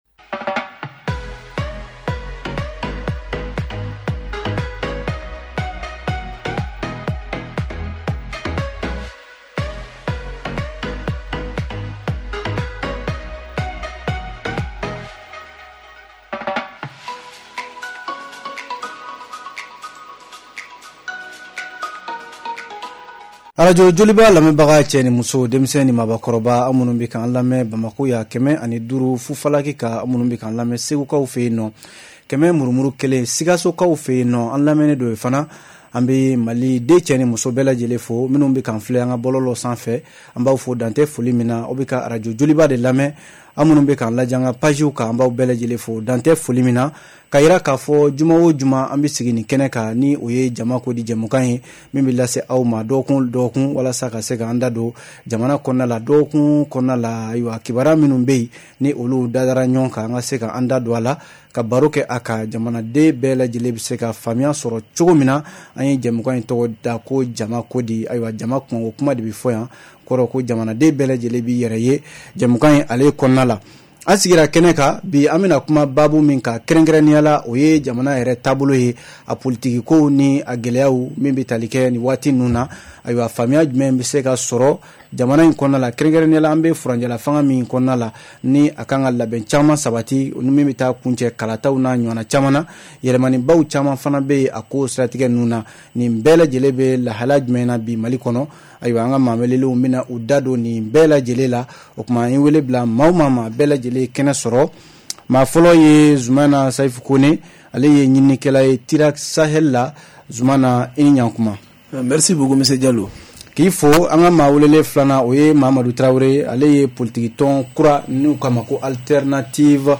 "Jama Ko Di" en direct ce vendredi 02 décembre 2022 à 20h30, sur la page facebook de Joliba FM et sur l'antenne de Joliba FM.